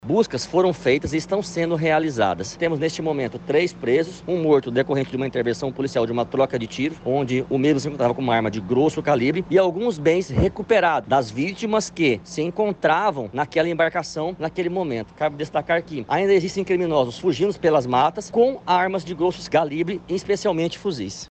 O delegado ressalta que as investigações continuam a fim de localizar e prender os outros membros da quadrilha.